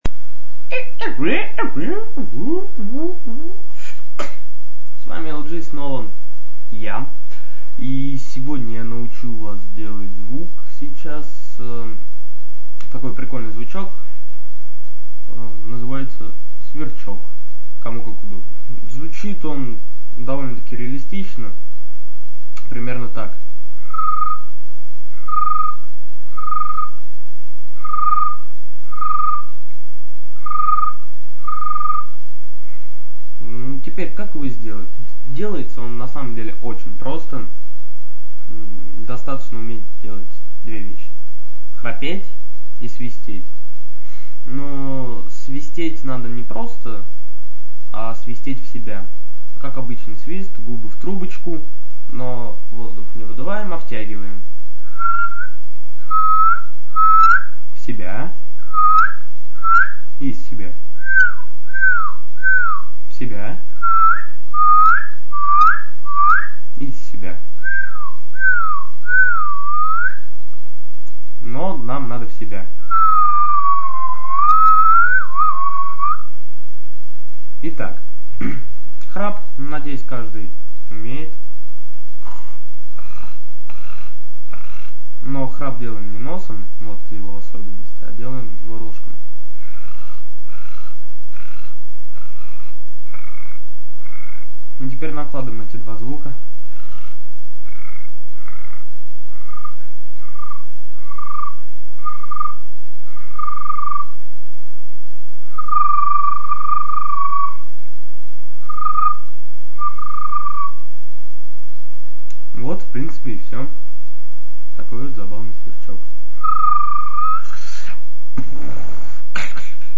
аудиоурок - вечерний сверчок)))
аудио урок
И звук вечерний.
Блин... Получается храп, получается свист в себя, вместе не получается...